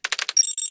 minikeyboard.ogg